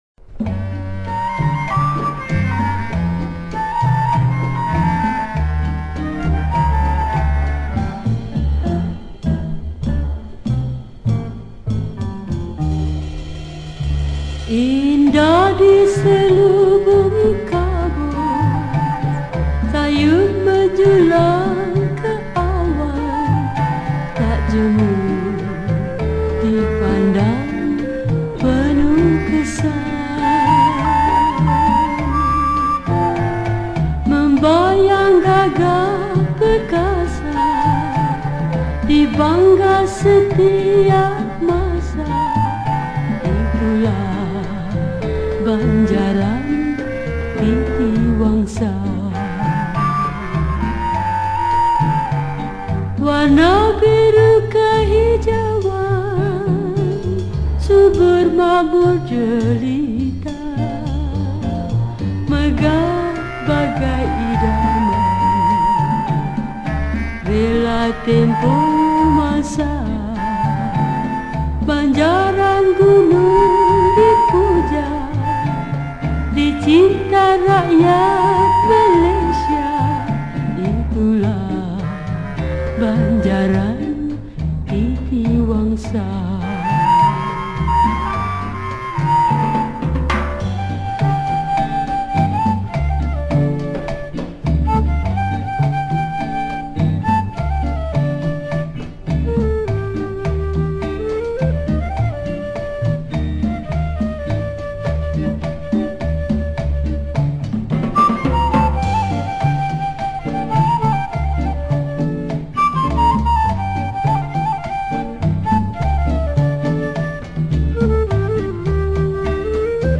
Malay Song
Skor Angklung